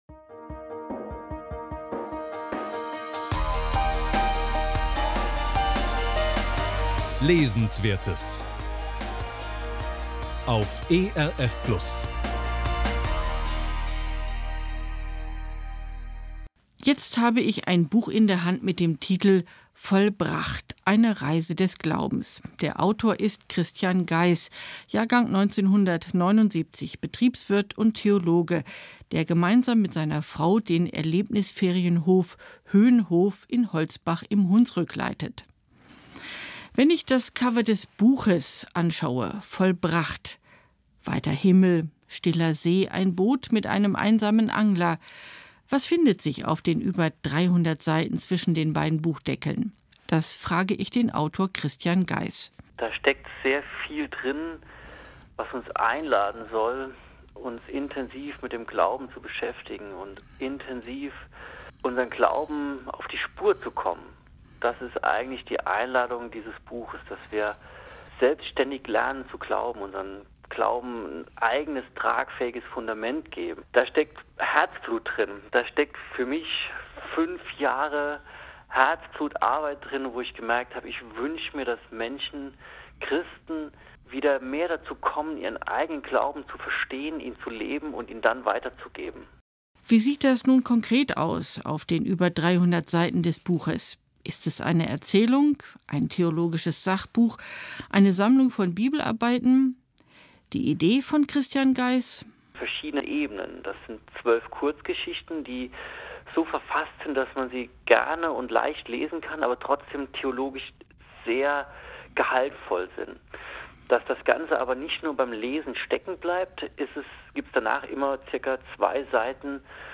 Buchvorstellung Vollbracht_ohne Musik
Buchvorstellung-Vollbracht_ohne-Musik.mp3